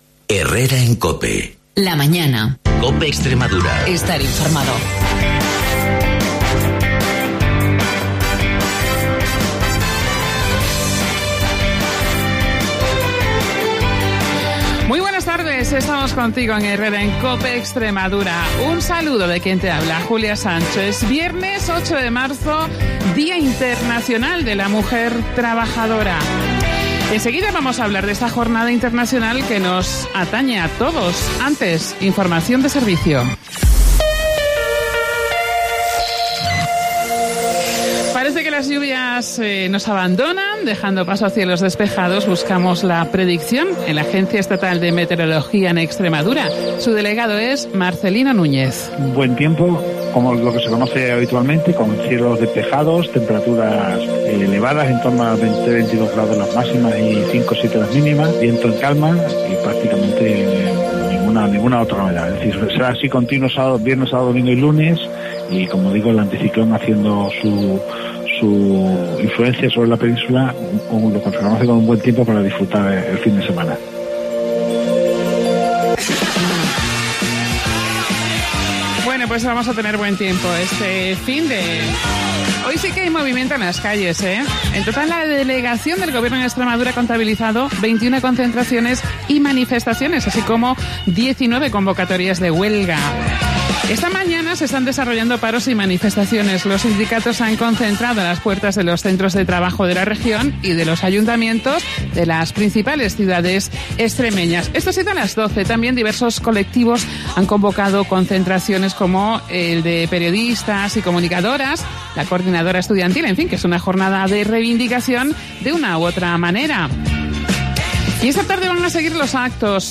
Además, hemos salido a la calle a preguntar a los pacenses qué opinan de este día.